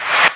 VEC3 Reverse FX
VEC3 FX Reverse 42.wav